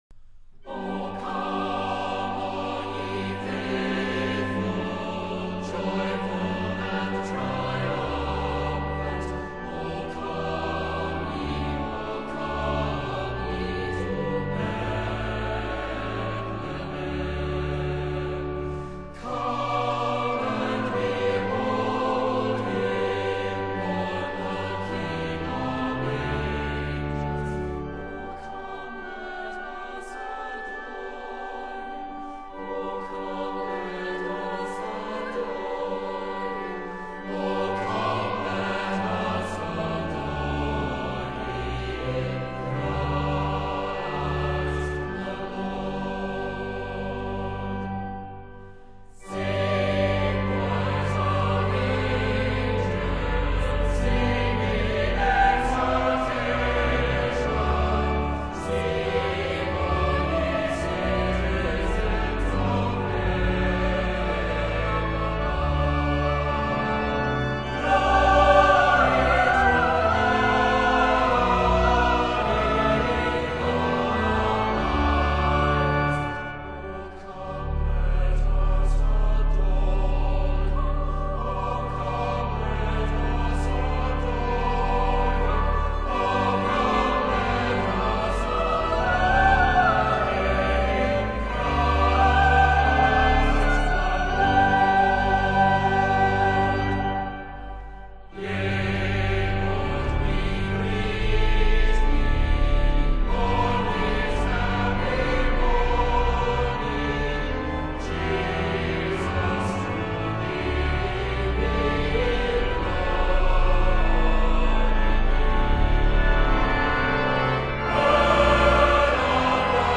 雖是教堂錄音，但合唱人聲極為清晰，堂韻殘響適度，堪稱中距離錄音的典範！